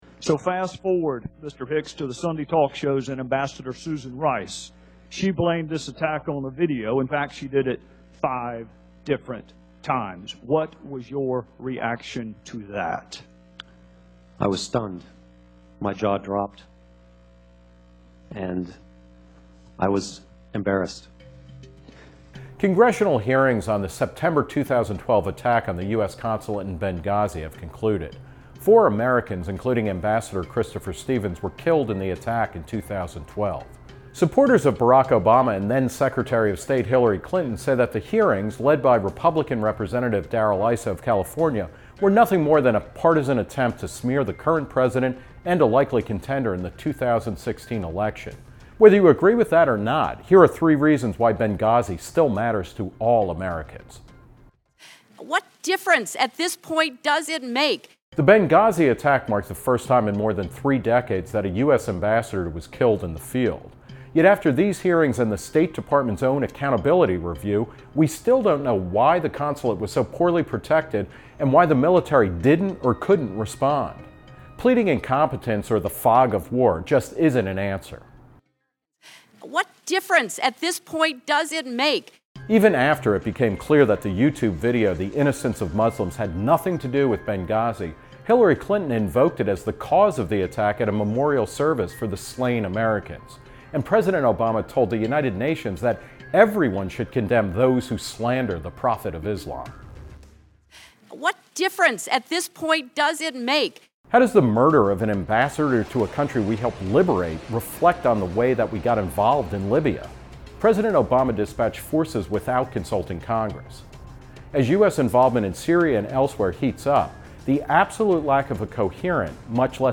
Written and narrated by Nick Gillespie.